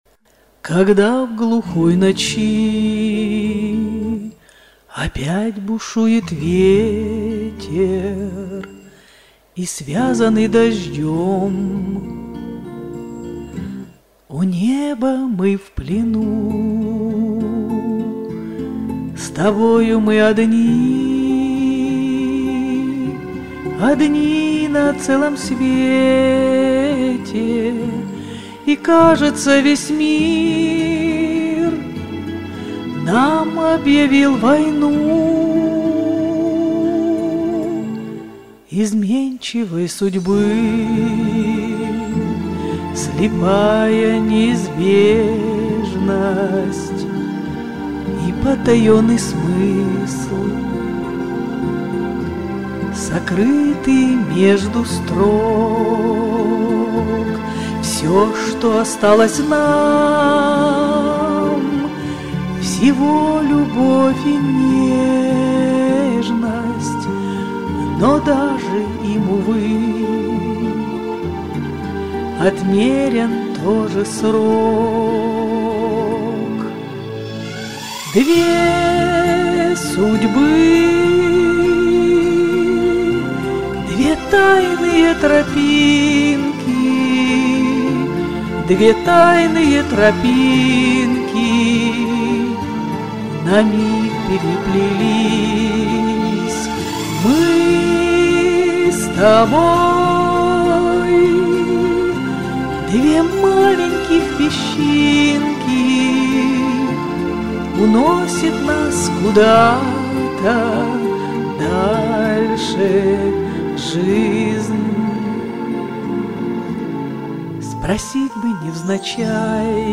Жанр: Романс